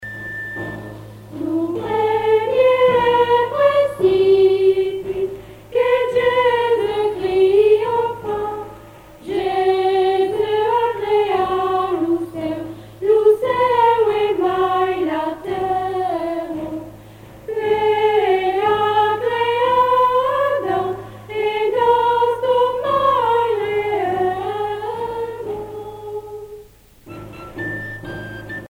Chants et danses traditionnelles
Pièce musicale éditée